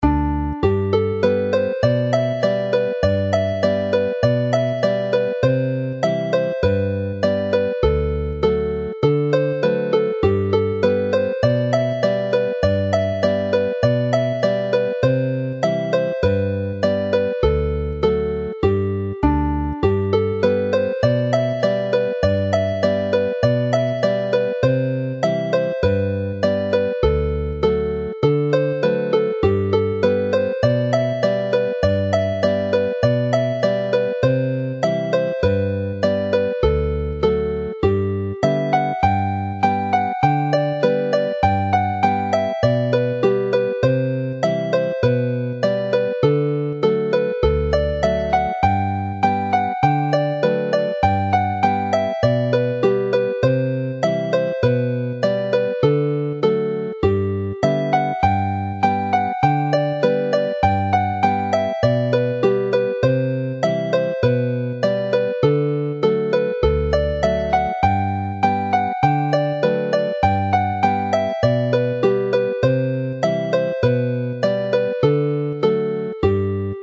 Alawon Cymreig - Set yr Aradr - Welsh folk tunes to play